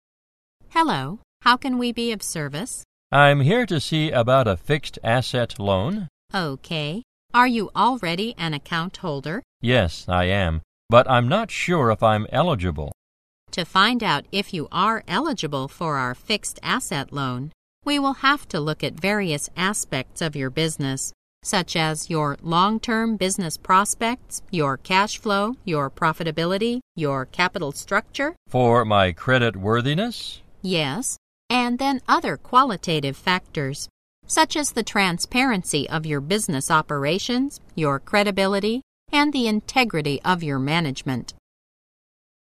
在线英语听力室银行英语情景口语 第99期:公司信贷业务 固定资产贷款情景(3)的听力文件下载, 《银行英语情景口语对话》,主要内容有银行英语情景口语对话、银行英语口语、银行英语词汇等内容。